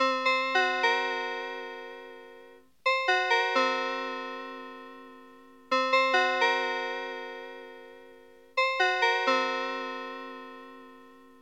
咕哝声和叹息声
描述：这是一个男人叹气和喘气的录音。它是用Zoom H6录音机和胶囊麦克风完成的。
声道立体声